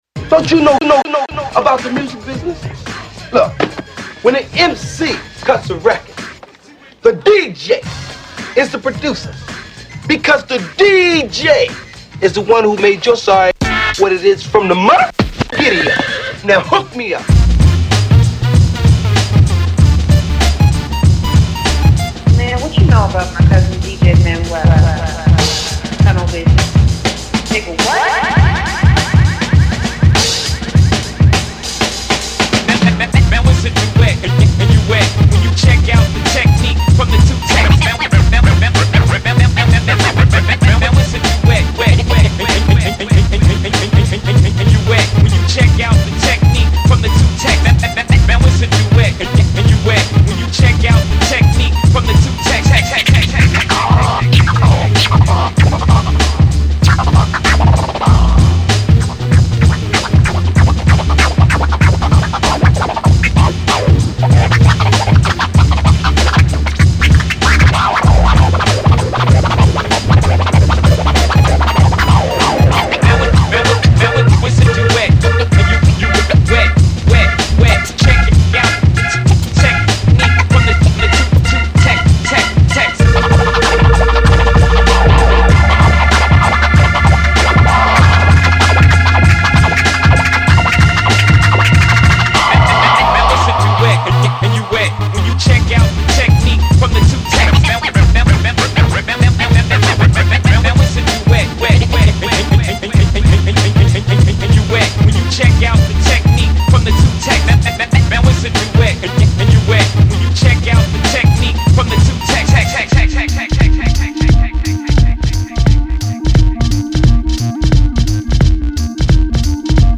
scratched